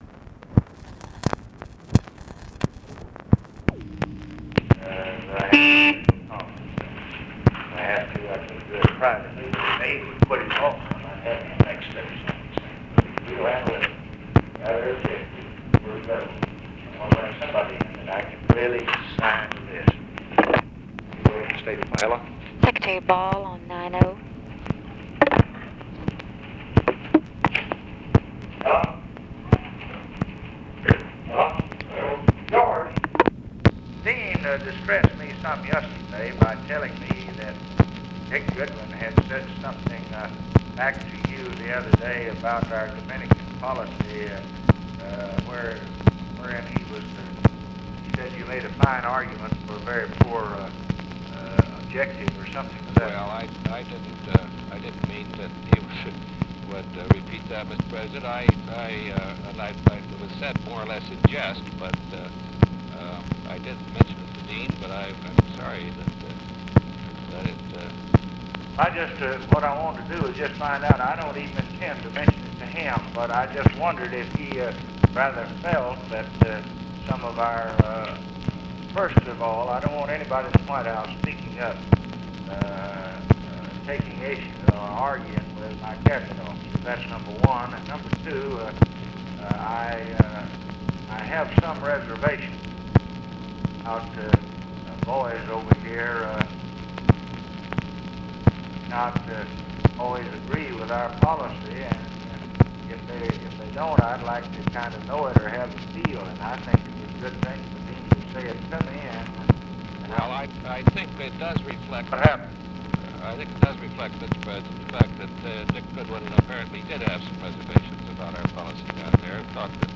Conversation with GEORGE BALL and OFFICE CONVERSATION, June 22, 1965
Secret White House Tapes